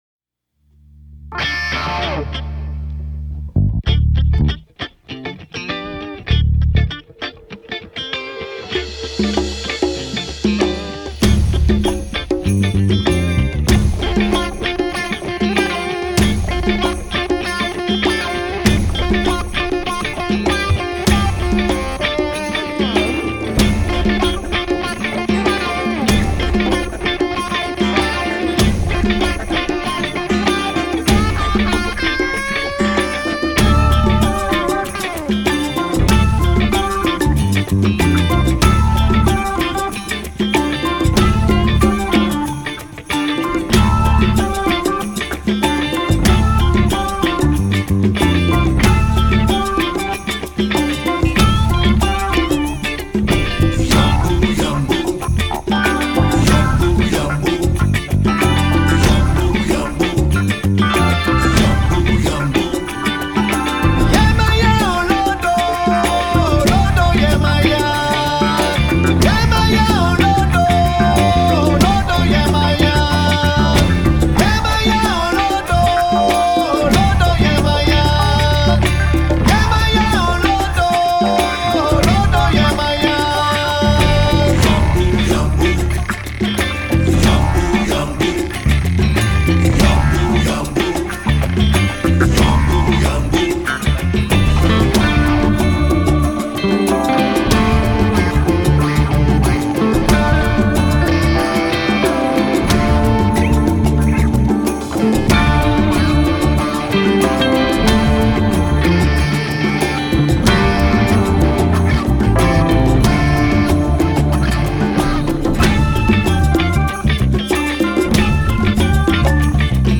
Genre: Classic Rock, Blues Rock